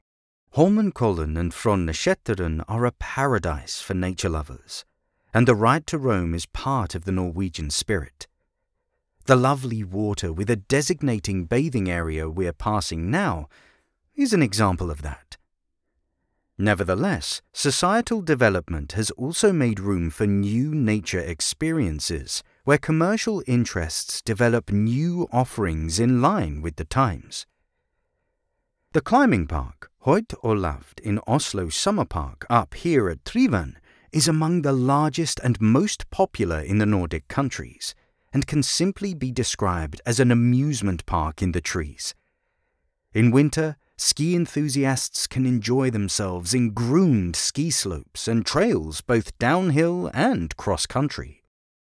English (British)
Commercial, Deep, Natural, Distinctive, Playful
Audio guide